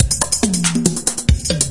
70 bpm Drum Loops " Native70
描述：用氢气制作的70bpm鼓循环
Tag: 节拍 电子